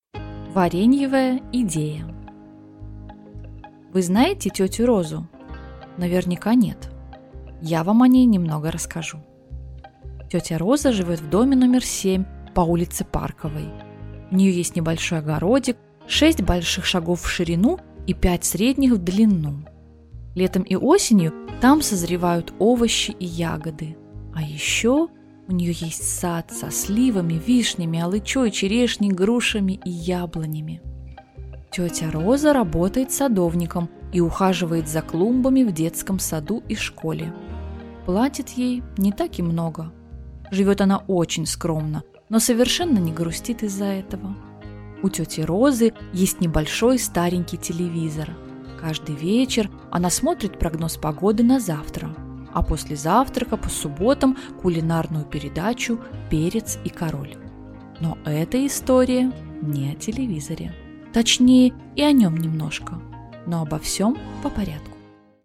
Лицо смерти (слушать аудиокнигу бесплатно) - автор Эллисон Майклс